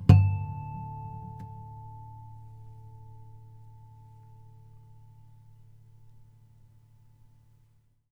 harmonic-10.wav